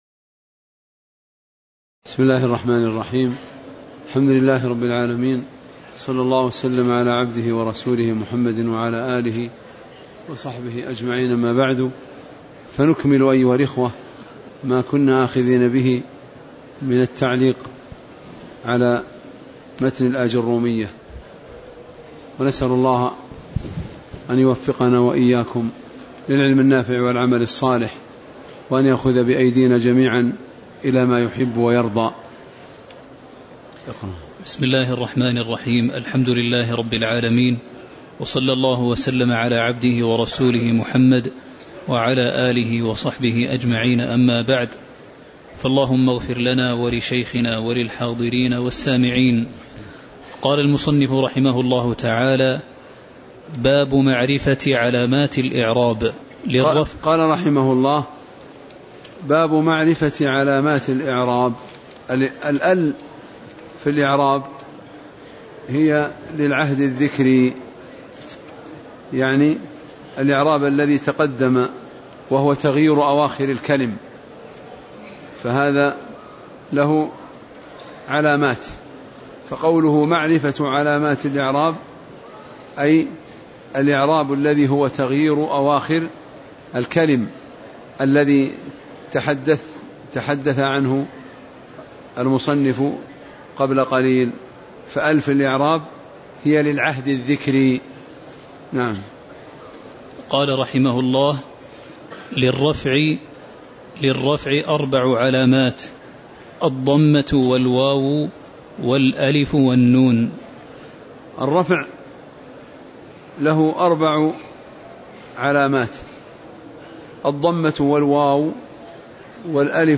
تاريخ النشر ٤ محرم ١٤٤٠ هـ المكان: المسجد النبوي الشيخ